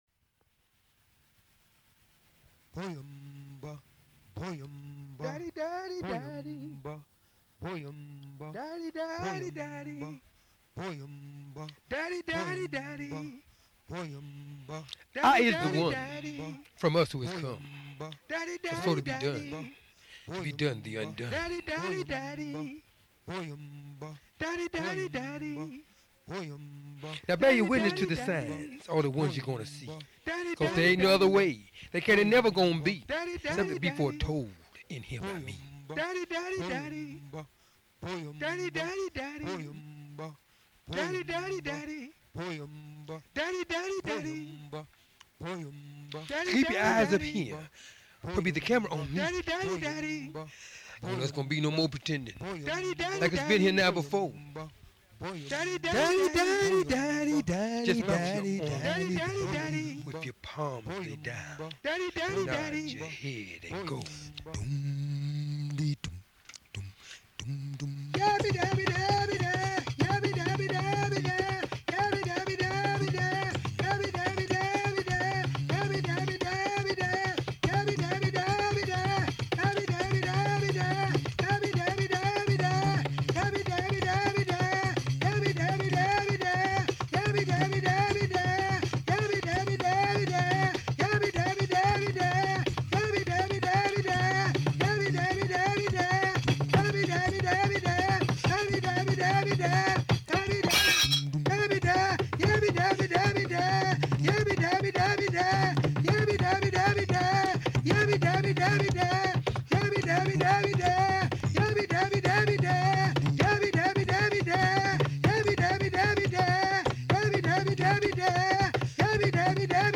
APOS-TOLL-LICKING-D (a tonal drawing written in poetic form)
The drumming voice, voiceovers, and shattering glass delivers a thought-provoking experience of nowness.